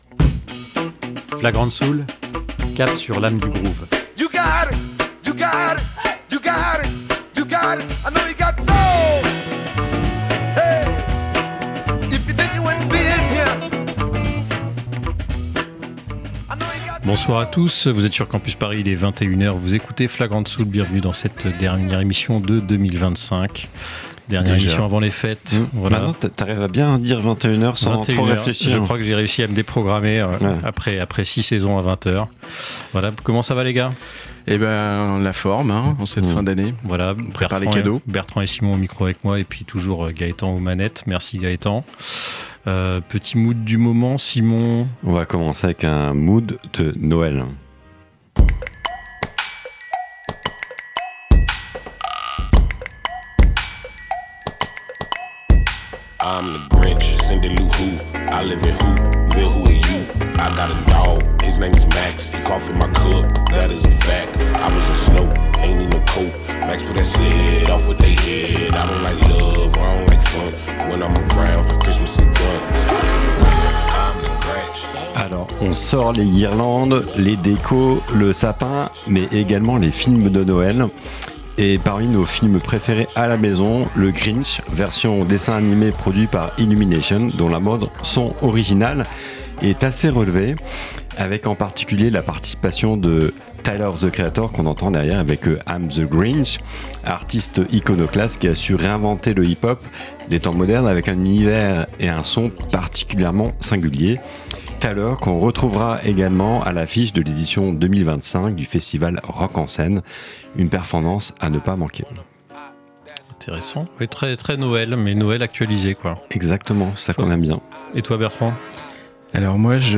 musique d'église funky
- son pour ton café/thé du matin
Hip-hop